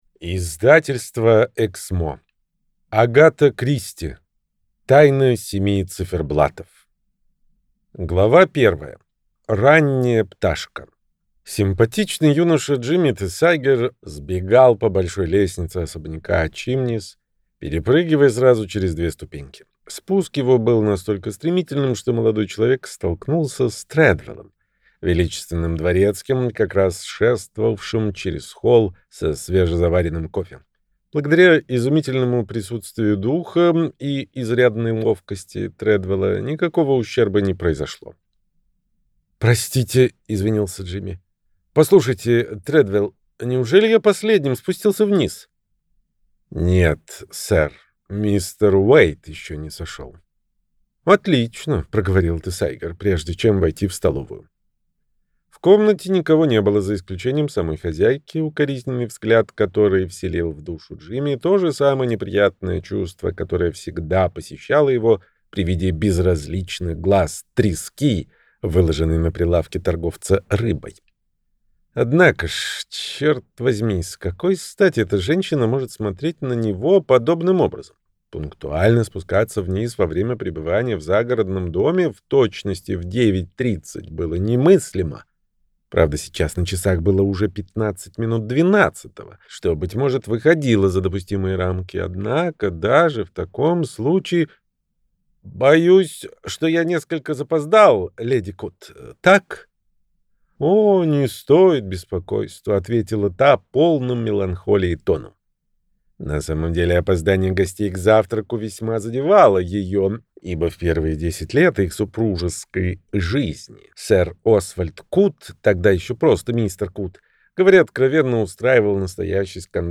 Аудиокнига Тайна семи циферблатов - купить, скачать и слушать онлайн | КнигоПоиск